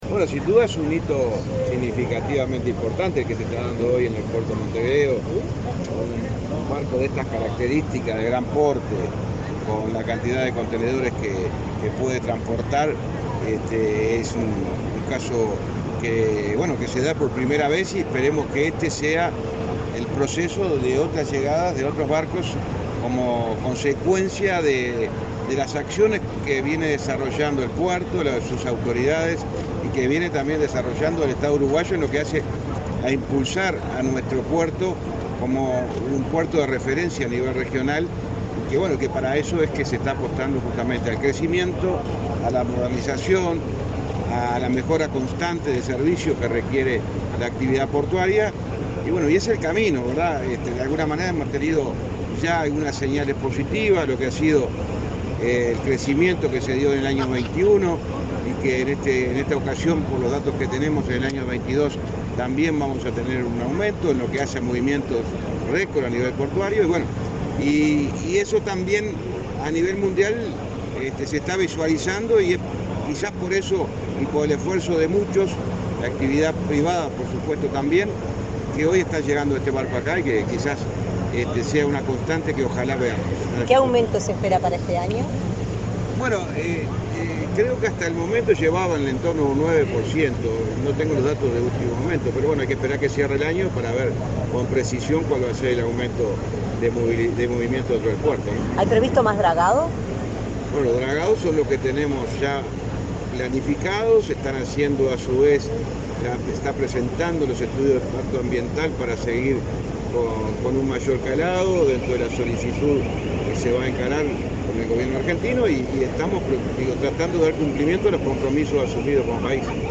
Declaraciones del ministro de Transporte, José Luis Falero
Declaraciones del ministro de Transporte, José Luis Falero 25/10/2022 Compartir Facebook X Copiar enlace WhatsApp LinkedIn El ministro de Transporte, José Luis Falero, dialogó con la prensa acerca de la llegada al puerto de Montevideo del buque portacontenedores Amazon, de la naviera japonesa Ocean Network Express (ONE).